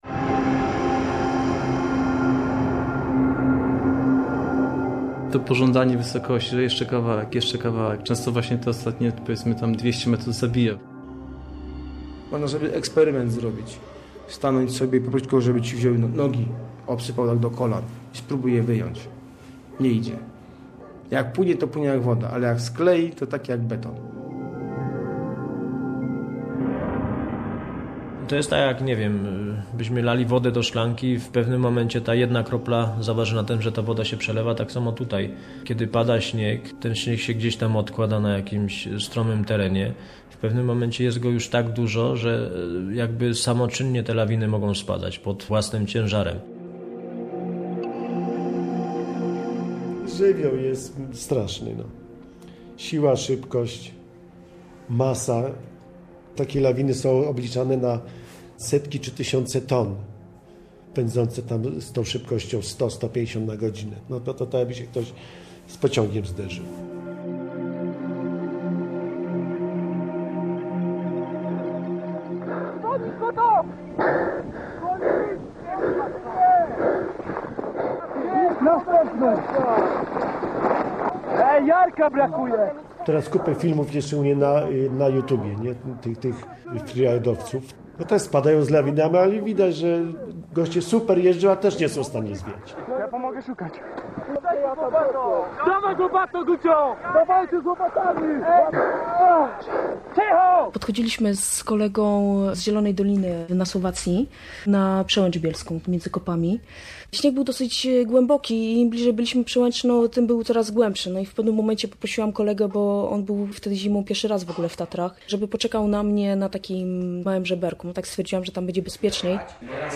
Biały pociąg - reportaż